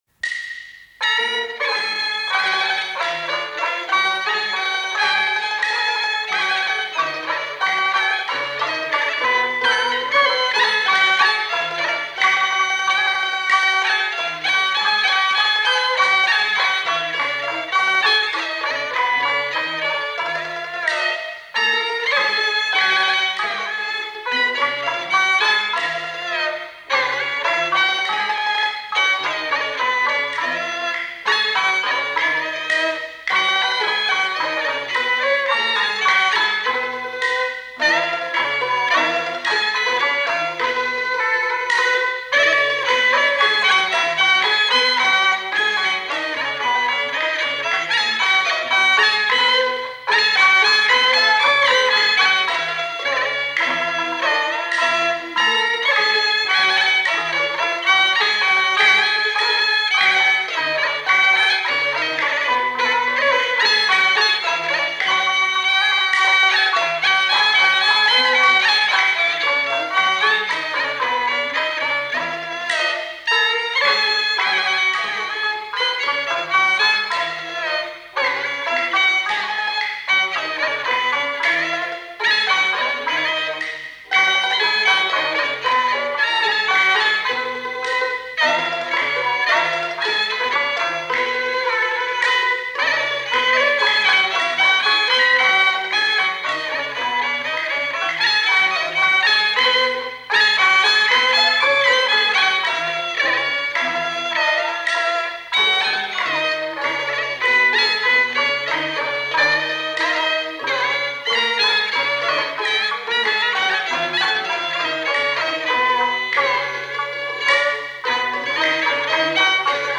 0038-京胡名曲新八岔.mp3